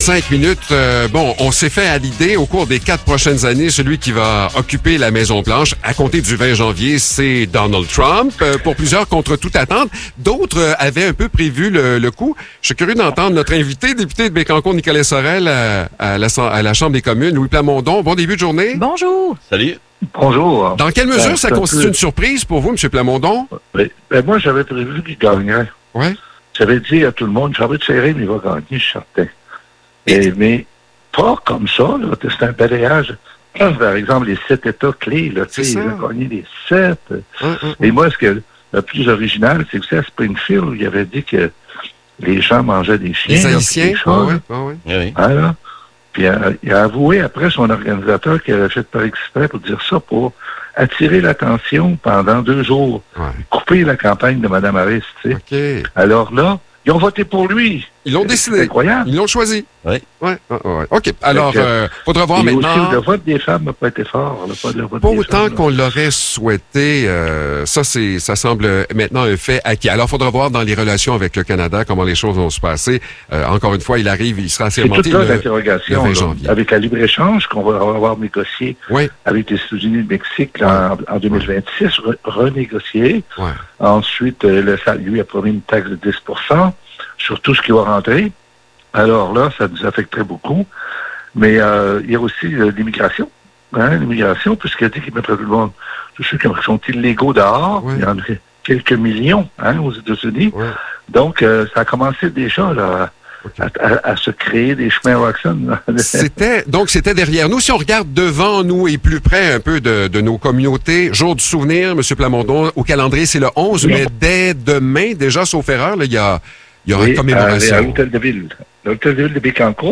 Échange avec Louis Plamondon - VIA 90.5